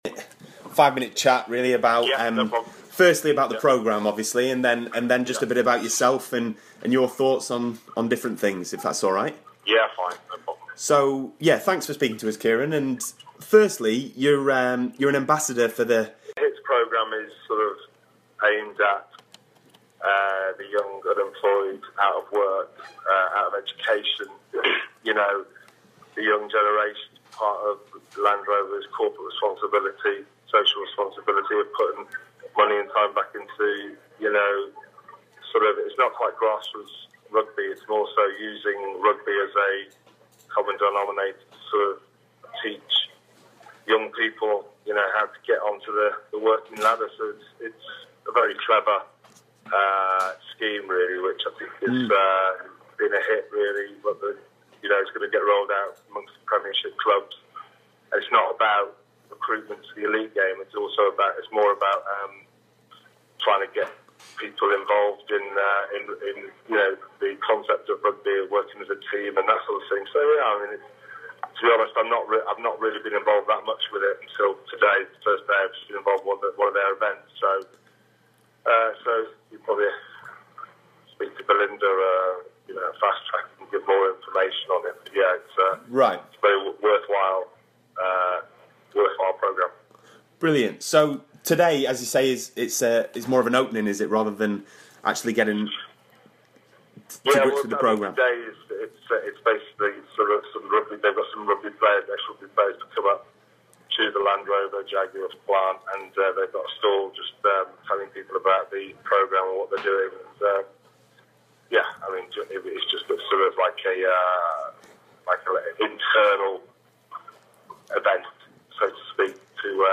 chats to England rugby legend and World Cup winning scrum-half, Kyran Bracken about all things rugby